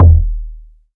Kicks
KICK.115.NEPT.wav